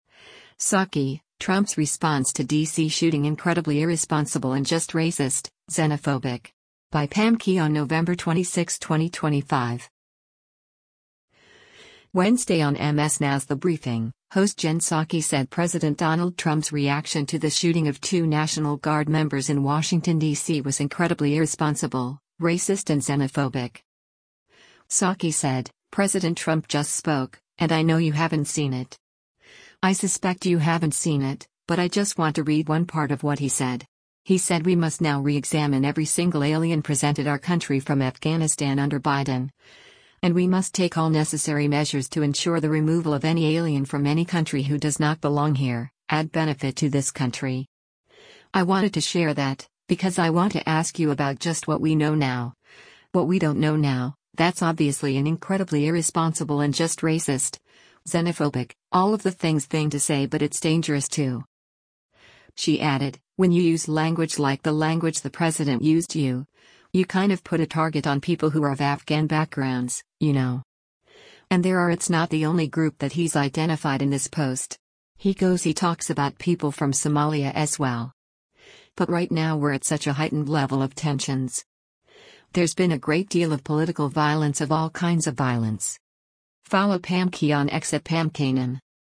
Wednesday on MS NOW’s “The Briefing,” host Jen Psaki said President Donald Trump’s reaction to the shooting of two National Guard members in Washington, D.C. was incredibly irresponsible, racist and xenophobic.